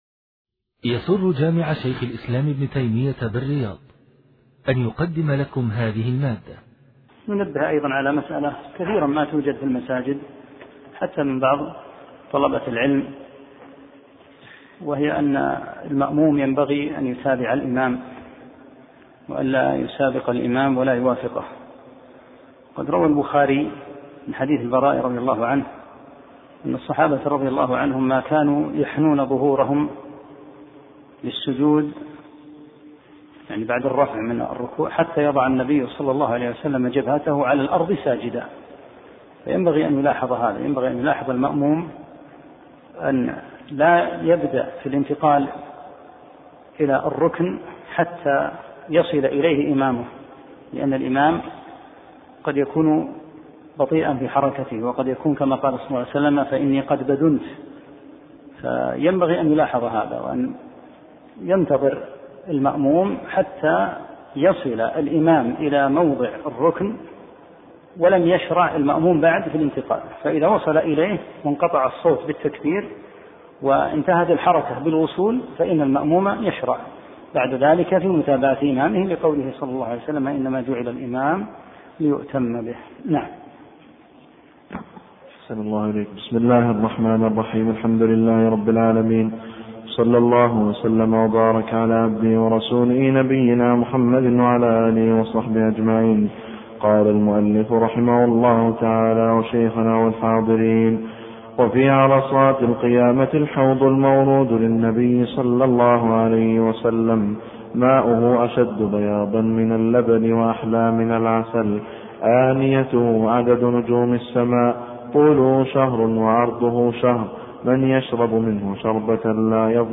7- الدرس السابع